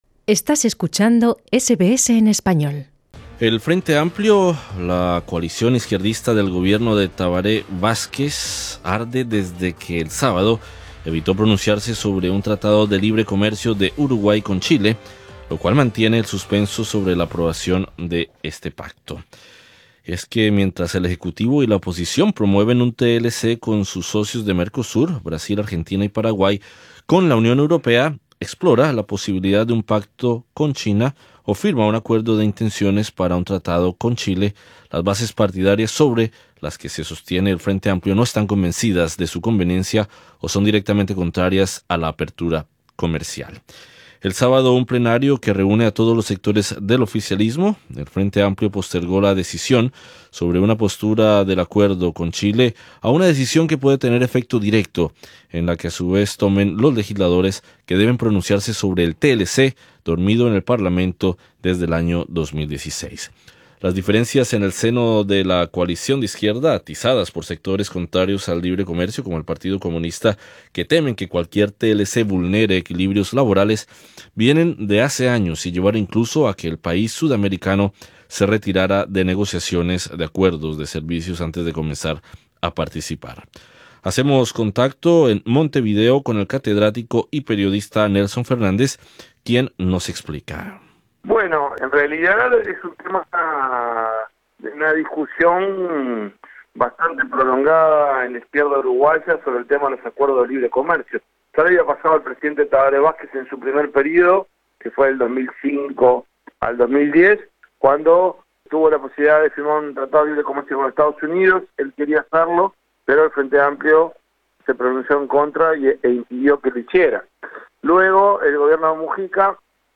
Entrevista en Montevideo